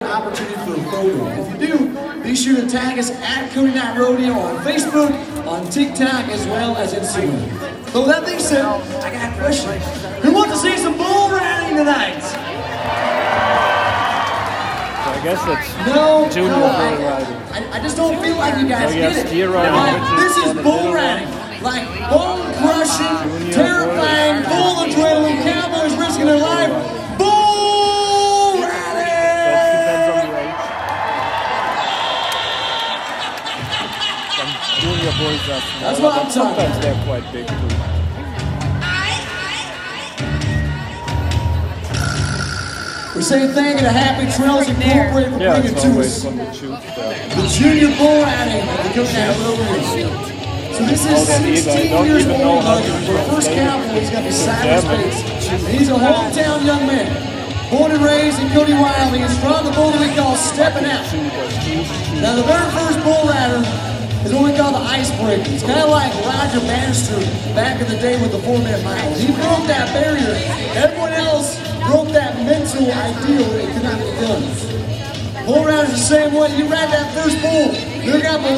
The build-up, the music, the excitement, the fall, the buzzer.
Rodeo sound
11_cody_rodeo.mp3